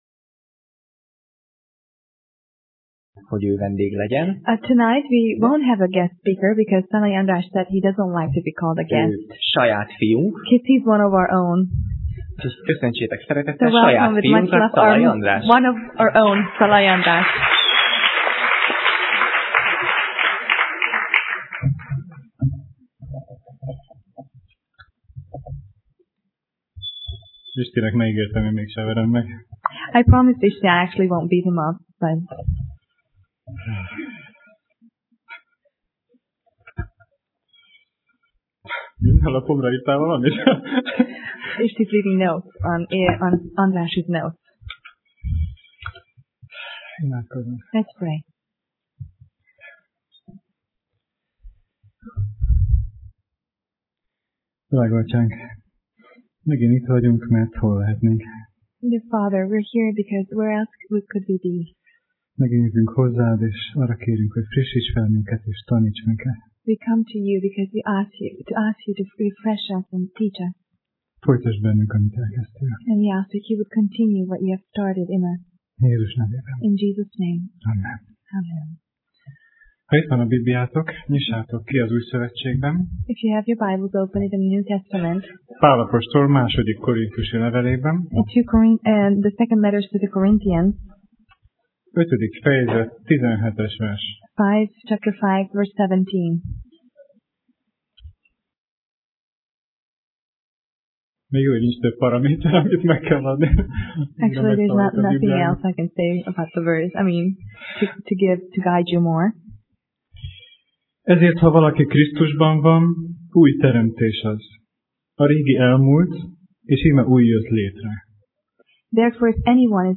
Tematikus tanítás
Alkalom: Szerda Este